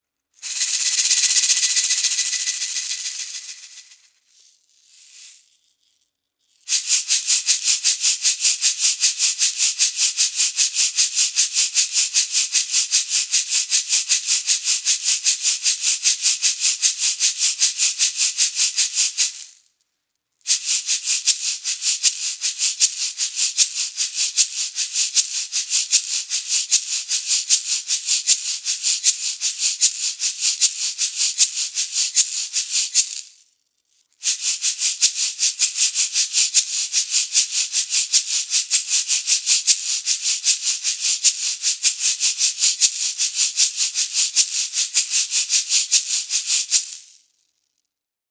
MEINL Percussion Studiomix Shaker - Medium (SH12-M-BK)
The StudioMix Shakers have a crisp full sound and come in two sizes. The textured surface both enhances the timbre and enables a positive grip.